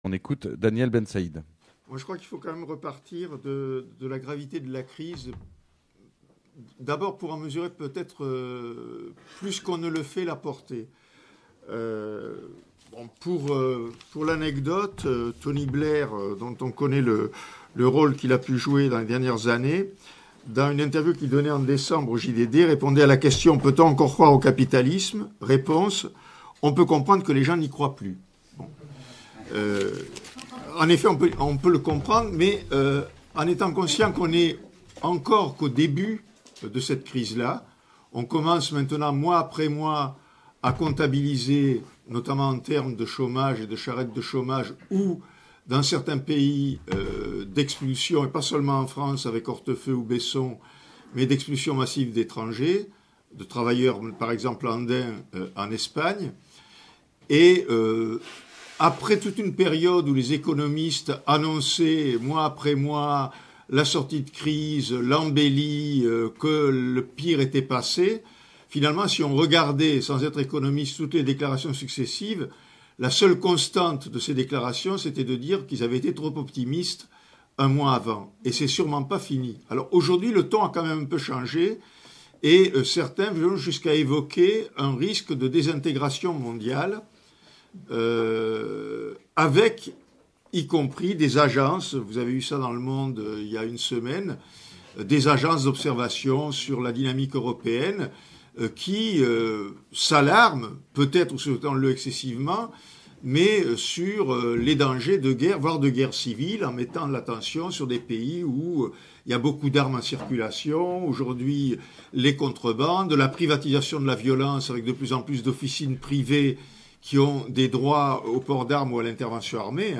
L’entretien radiophonique a été diffusé par la radio Fréquence Paris Plurielle dans le cadre de l’émission Les oreilles loin du front.
Diffusion d’une réunion publique avec Daniel Bensaïd dans un café parisien du XXe arrondissement autour de la crise en France et dans le monde et de ses conséquences prévisibles.